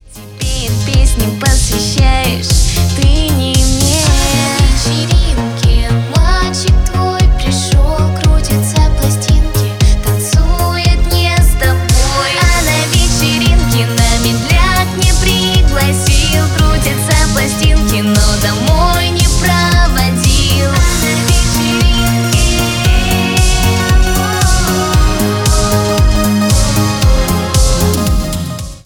Гитара
Pop rock Женский голос Поп